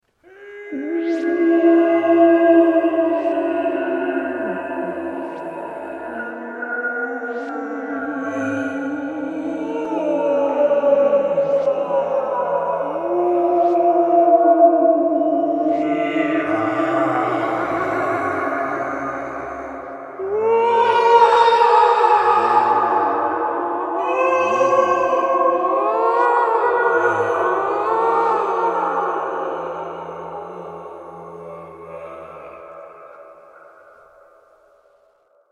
دانلود صدای ناله و درد برزخی از ساعد نیوز با لینک مستقیم و کیفیت بالا
جلوه های صوتی